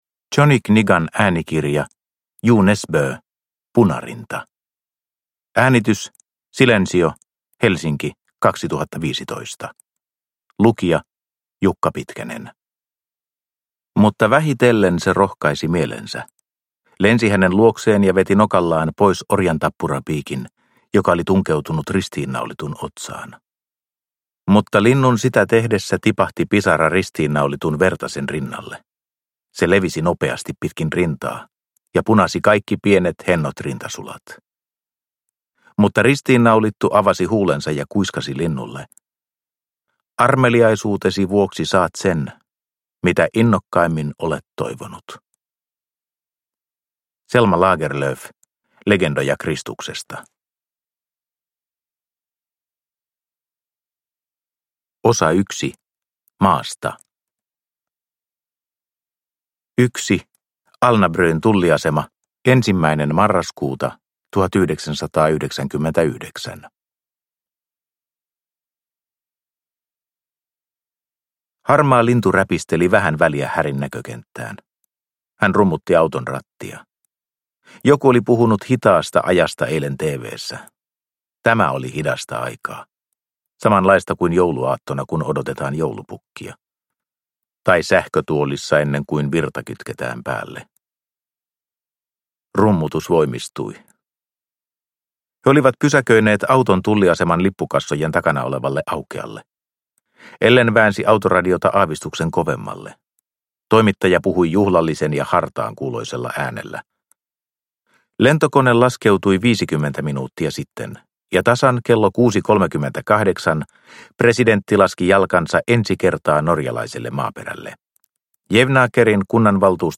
Punarinta (ljudbok) av Jo Nesbø